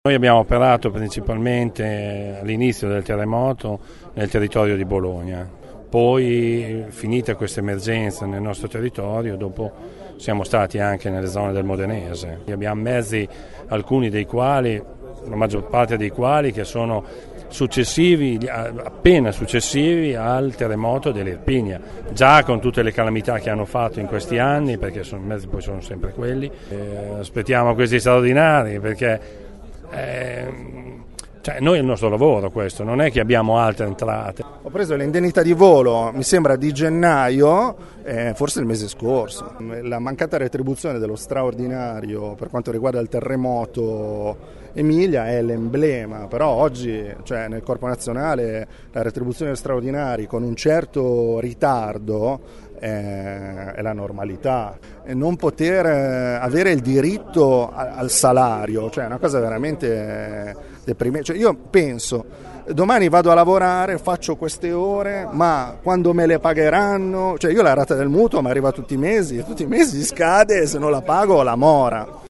Le voci dei vigili del fuoco